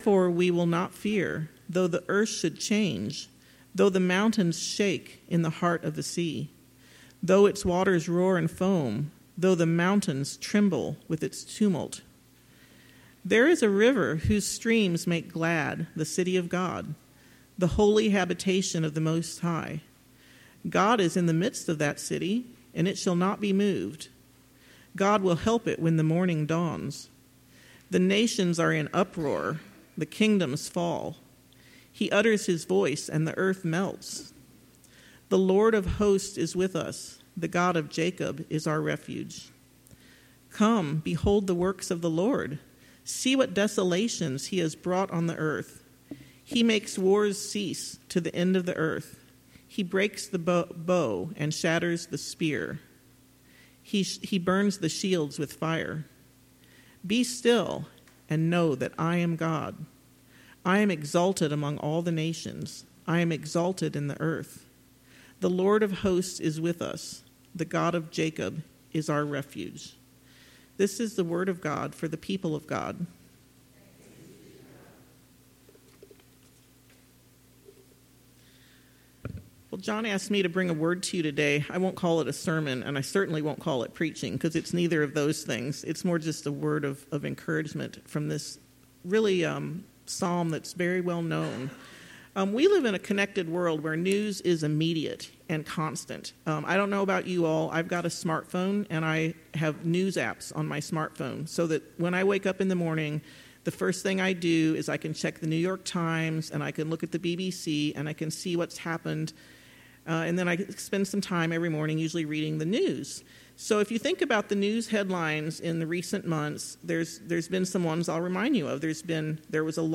The Sermon